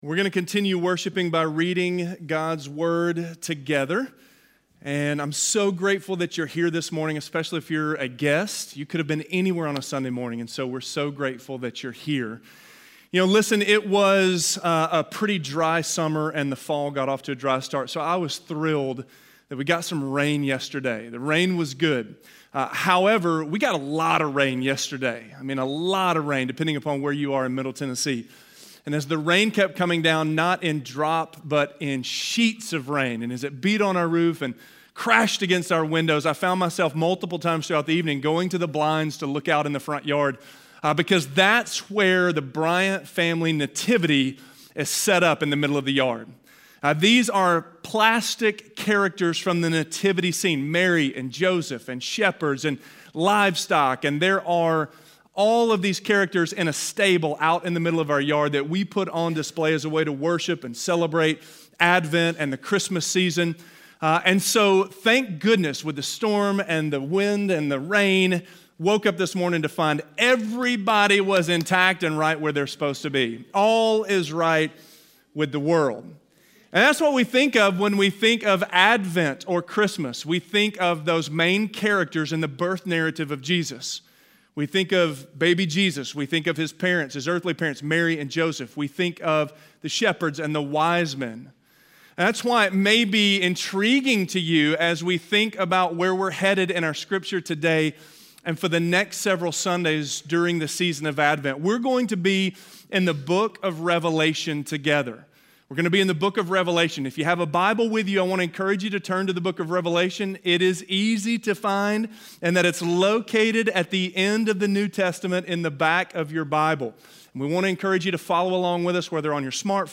The Throne - Sermon - Avenue South